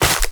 Sfx_creature_penguin_hop_land_05.ogg